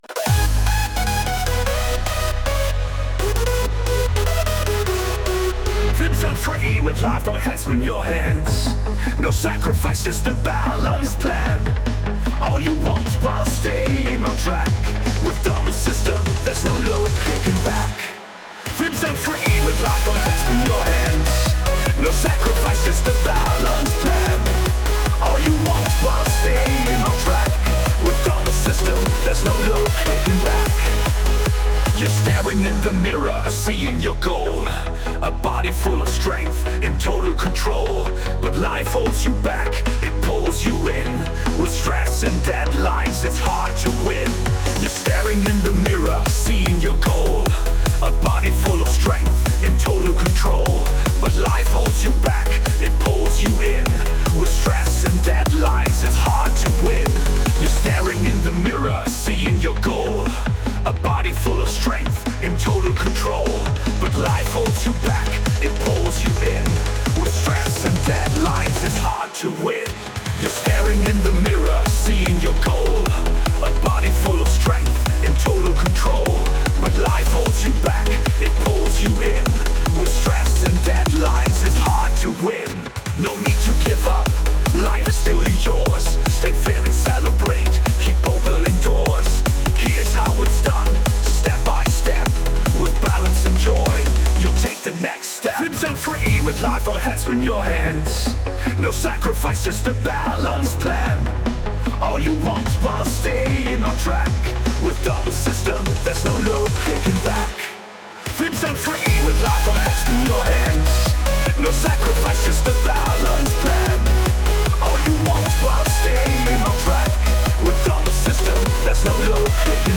Kostenlose Lieder mit motivierenden Texten (und geilem Beat ;‒)